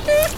クーコールが聞けます。
大平山3歳のクーコール